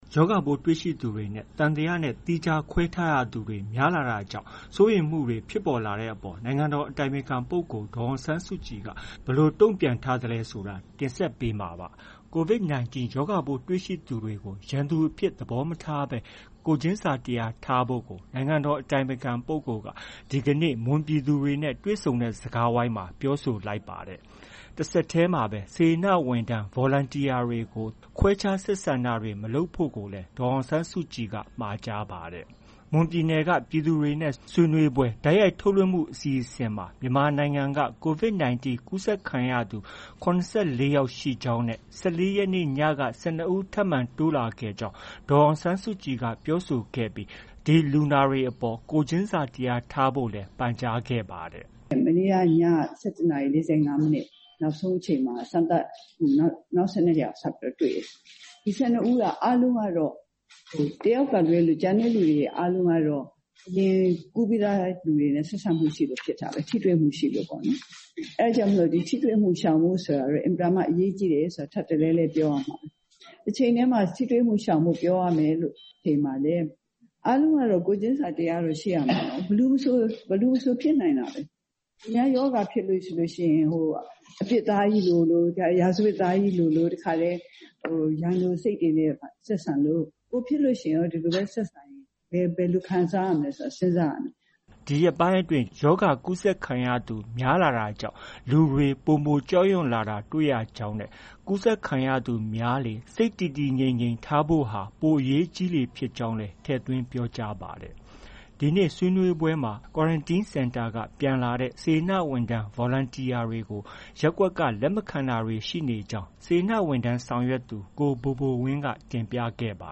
မွန်ပြည်နယ်က ပြည်သူတွေ ဆွေးနွေးပွဲ တိုက်ရိုက်ထုတ်လွှင့်မှု အစီအစဉ်မှာ မြန်မာနိုင်ငံမှာ COVID-19 ကူးစက်ခံရသူ ၇၄ ယောက်ရှိကြောင်းနဲ့ ၁၄ ရက်နေ့က ၁၂ ဦးထပ်မံတိုးလာခဲ့ကြောင်း ဒေါ်အောင်ဆန်းစုကြည်က ပြောဆိုခဲ့ပြီး ဒီလူနာတွေအပေါ် ကိုယ်ချင်းစာတရားထားကြဖို့လည်း ပန့်ကြားခဲ့ပါတယ်။